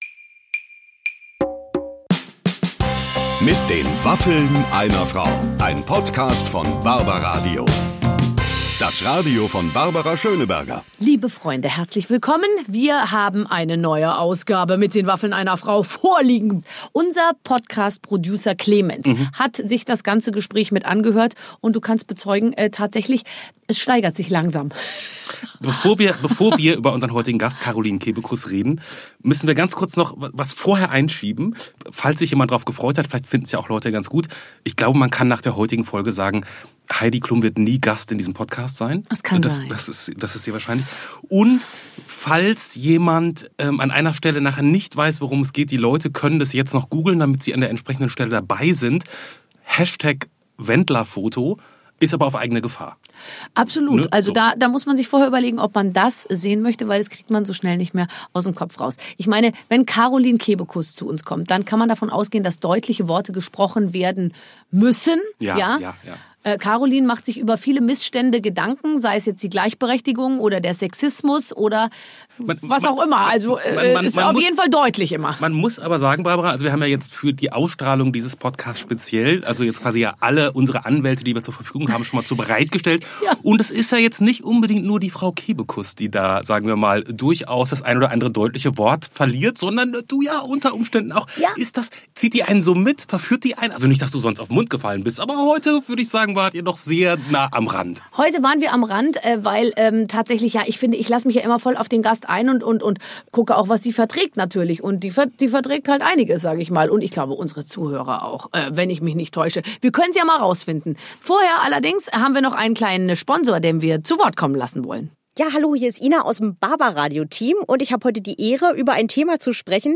Jedes Wochenende trifft Barbara Schöneberger Prominente aus Musik, Fernsehen, Sport und Showbiz.